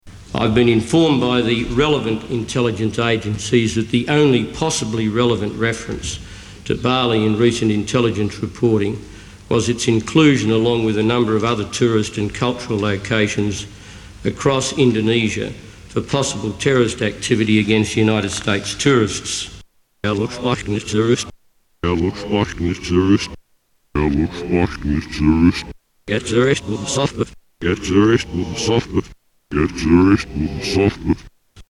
Tags: Science Reverse Speech Analysis Politics Backward Messages Queen of England